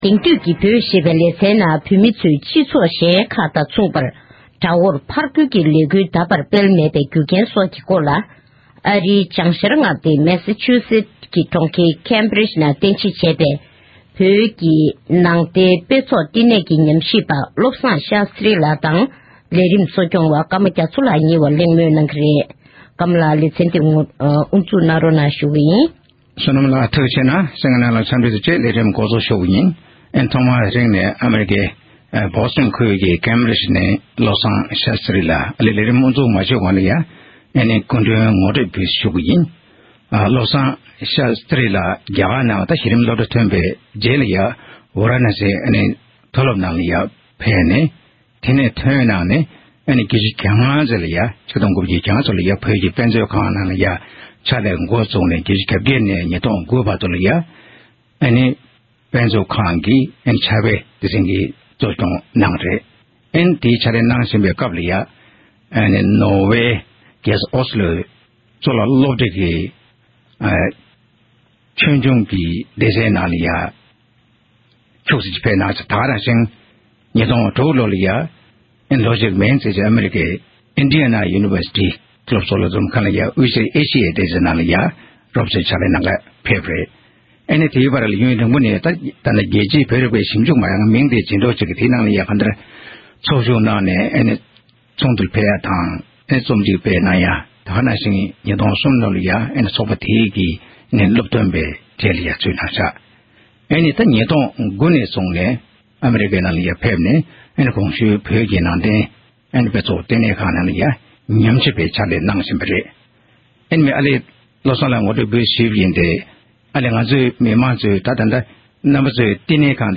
གླེང་མོལ་གནང་བར་གསན་རོགས༎